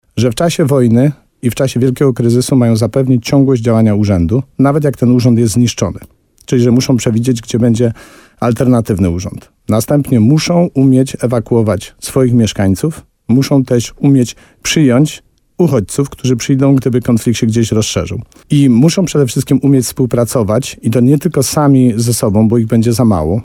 – mówił wójt Tadeusz Królczyk w programie Słowo za Słowo na antenie RDN Nowy Sącz.